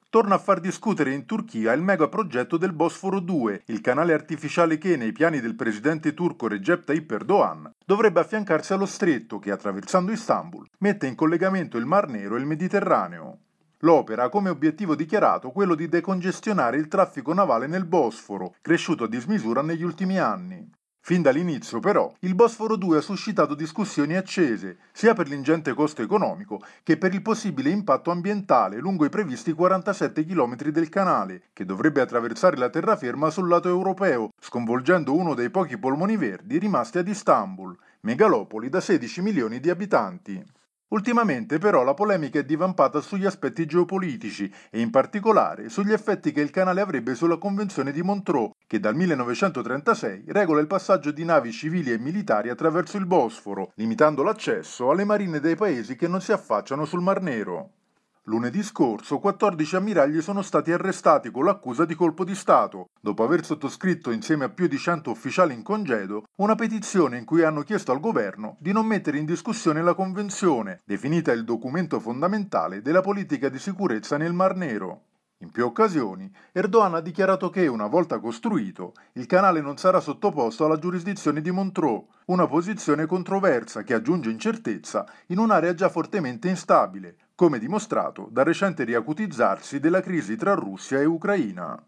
per il GR di Radio Capodistria